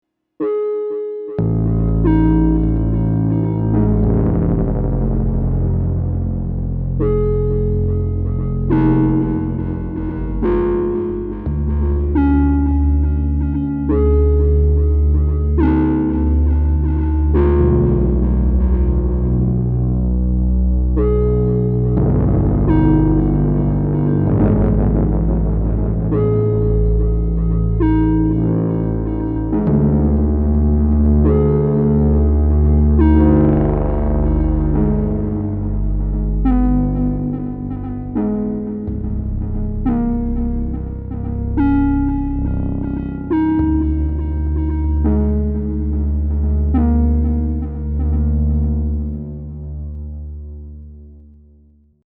Perkons, OT, Sherman and Boss BX. Perkons is the only sound source.
Recording sounds like shit, but you get the idea…